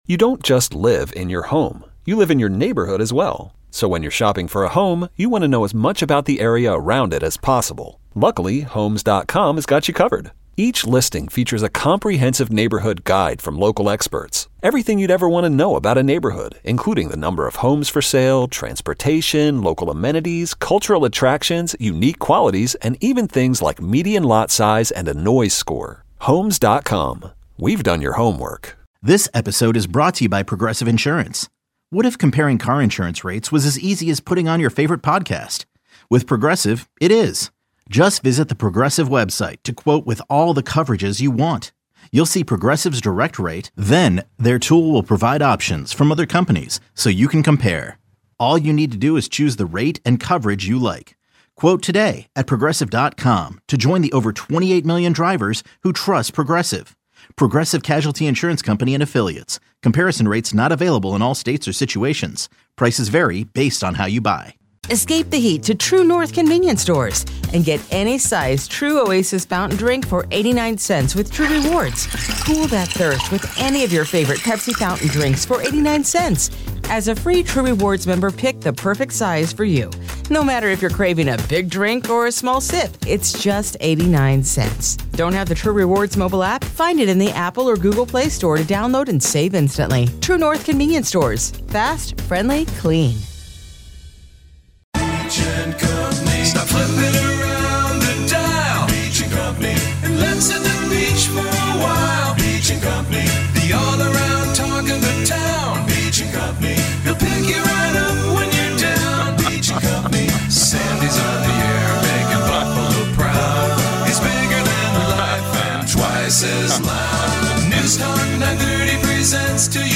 Taking your calls on what is going on in Seattle.